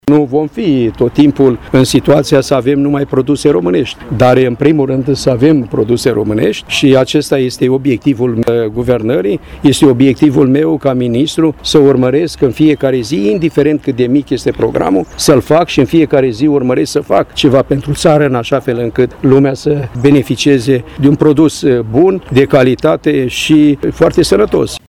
Una din problemele ridicate a fost legată de diminuarea importului de produse alimentare, concomitent cu sprijinul acordat producătorilor autohtoni, pentru desfacerea unor produse româneşti, după cum a explicat ministrul Petre Daea: